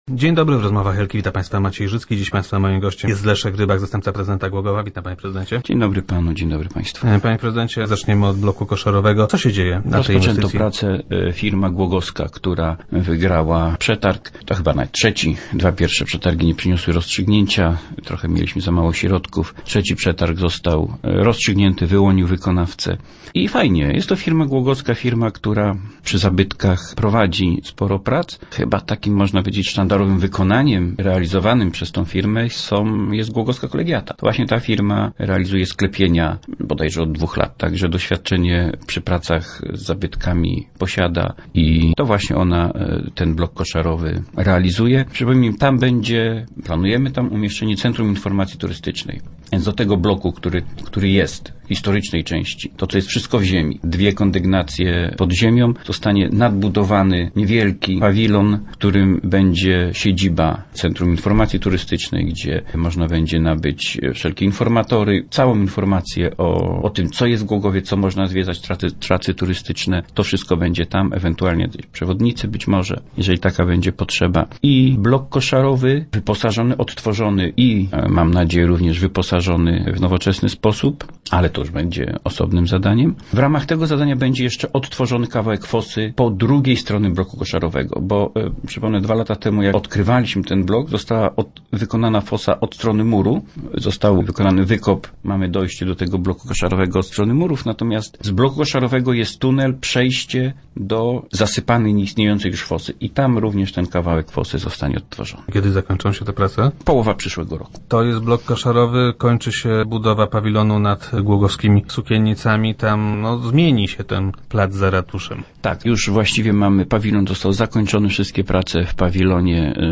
- Nie jest to jednak krytyka merytoryczna - twierdzi Leszek Rybak, zastępca prezydenta Głogowa, który był gościem Rozmów Elki.